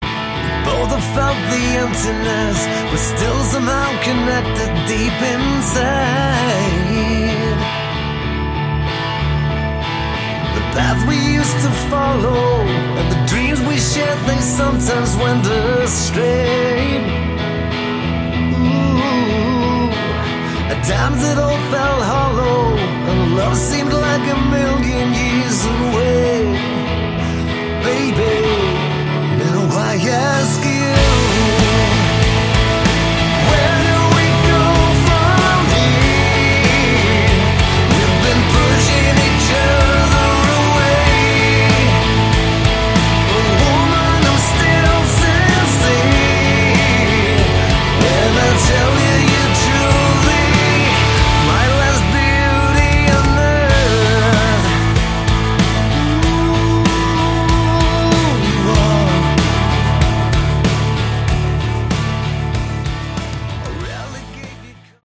Category: Hard Rock
drums
guitars
bass
vocals
keyboards